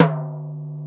TOM XC.TOM07.wav